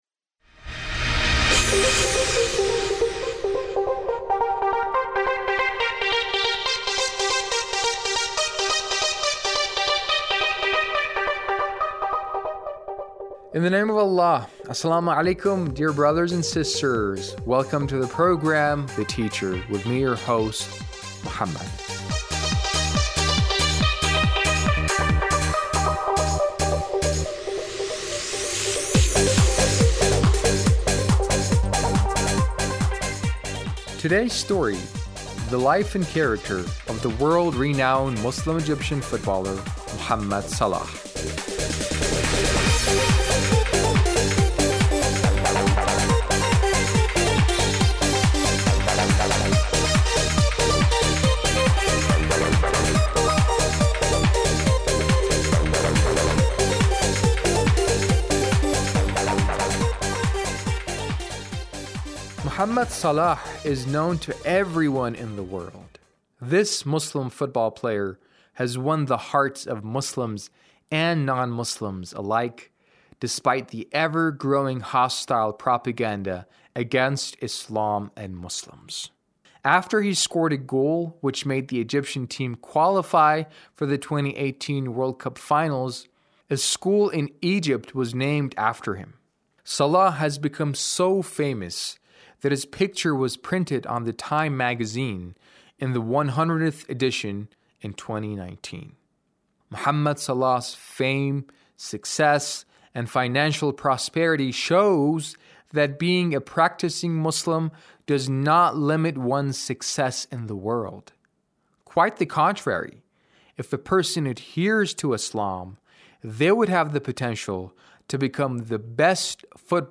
A radio documentary on the life of Mohamed Salah - 4